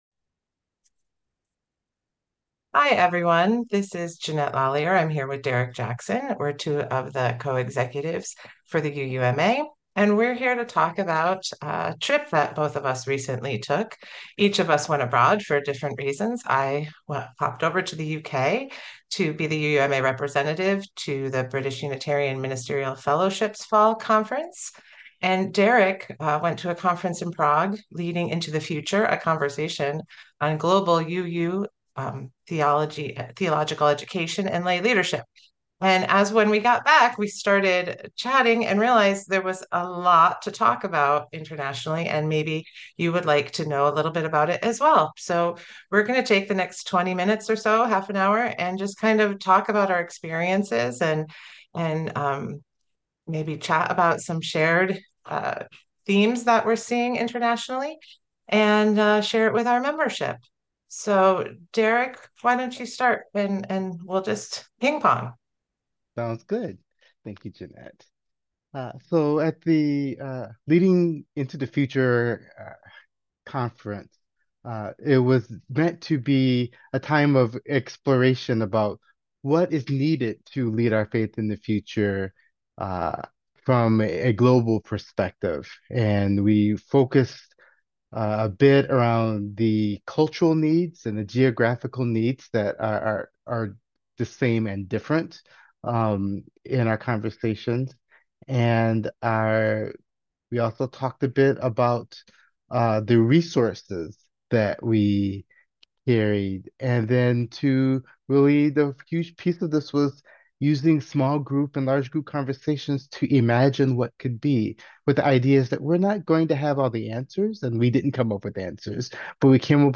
International-Conversation.mp3